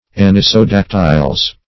\an`i*so*dac"tyls\ ([a^]n`[-i]*s[-o]*d[a^]k"t[i^]lz), n. pl.